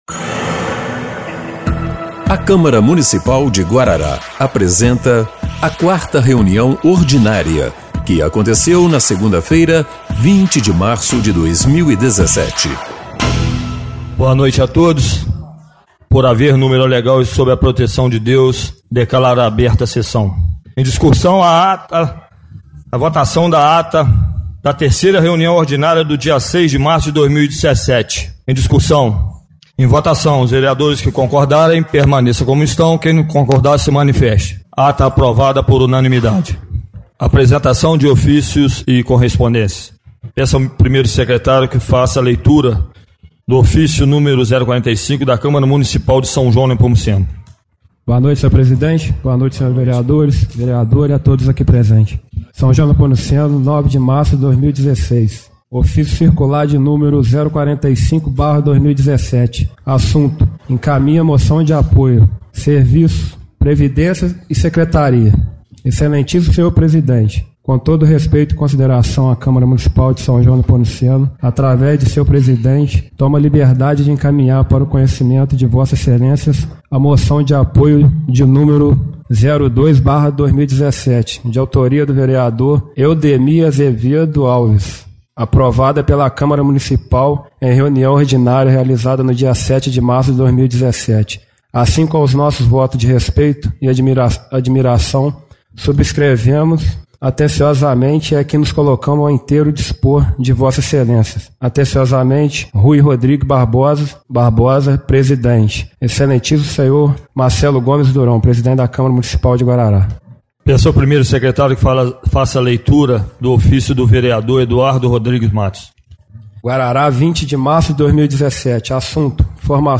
4ª Reunião Ordinária de 20/03/2017